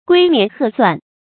龜年鶴算 注音： ㄍㄨㄟ ㄋㄧㄢˊ ㄏㄜˋ ㄙㄨㄢˋ 讀音讀法： 意思解釋： 見「龜年鶴壽」。